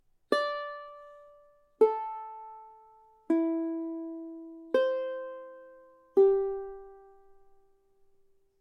Mientras que el timple estándar se afina de la siguiente manera:
• 5ª cuerda: sol
Así suena el TIMPLE cuerdas al aire
cuerdas-al-aire-timple.mp3